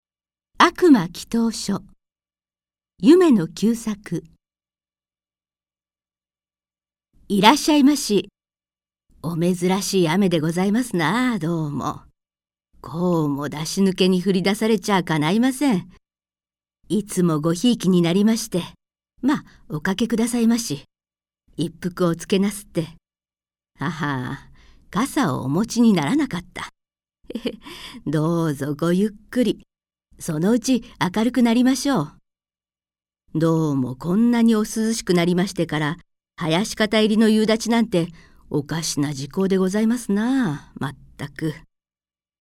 朗読ＣＤ　朗読街道71「悪魔祈祷書・瓶詰地獄」夢野久作
朗読街道は作品の価値を損なうことなくノーカットで朗読しています。